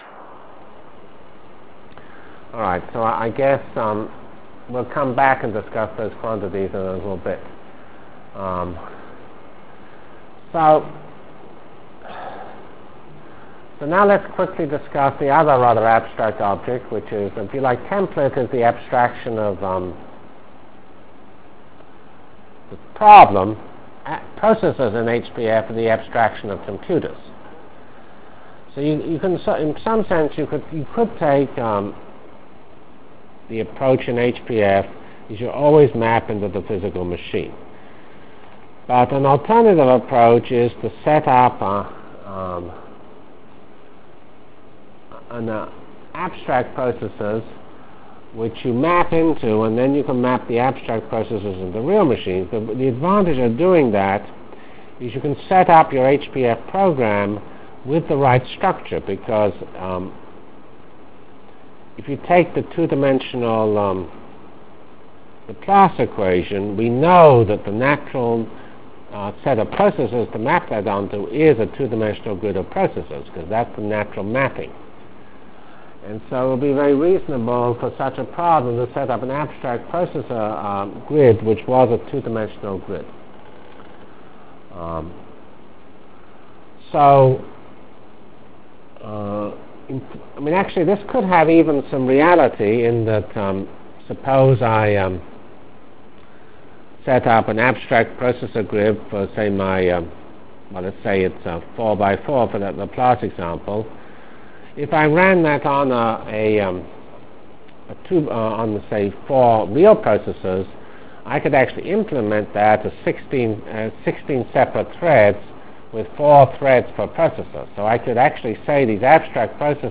From CPS615-Introduction to Virtual Programming Lab -- Problem Architecture Continued and Start of Real HPF Delivered Lectures of CPS615 Basic Simulation Track for Computational Science -- 26 September 96. *